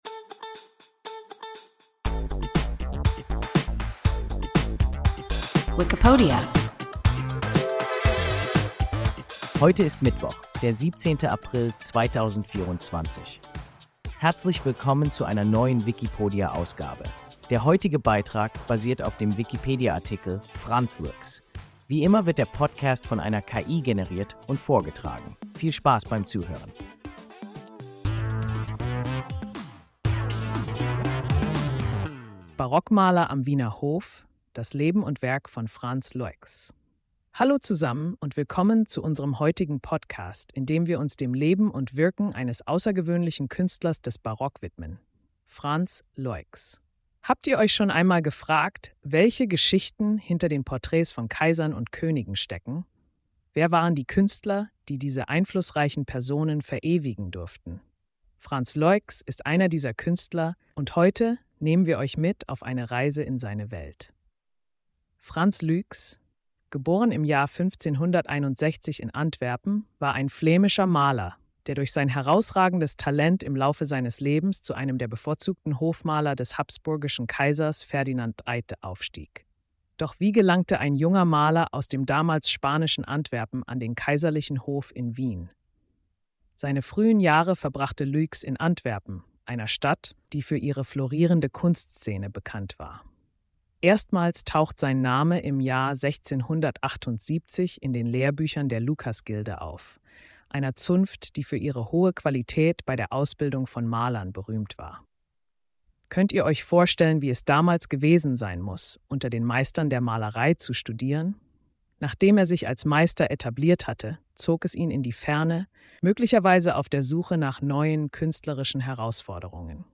Frans Luycx – WIKIPODIA – ein KI Podcast